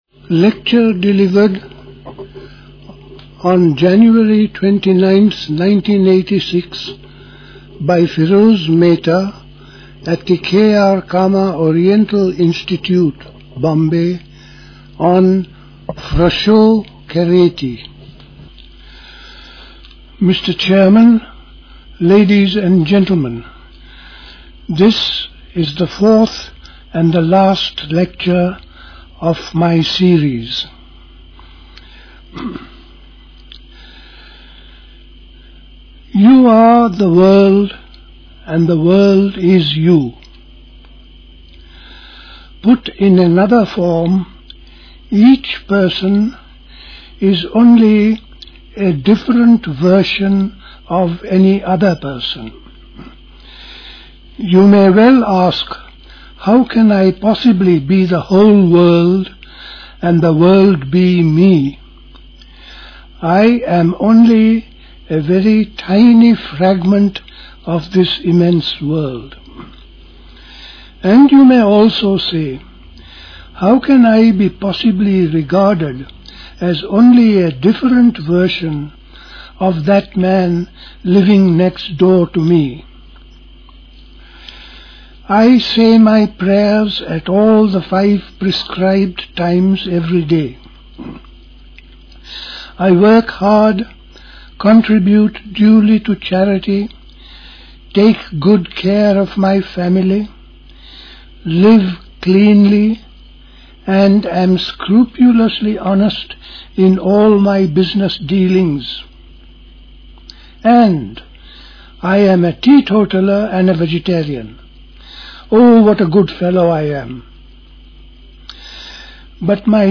Recorded in Bombay.